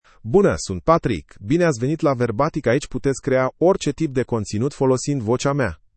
PatrickMale Romanian AI voice
Patrick is a male AI voice for Romanian (Romania).
Voice sample
Male
Patrick delivers clear pronunciation with authentic Romania Romanian intonation, making your content sound professionally produced.